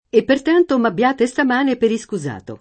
scuso [ S k 2@ o ]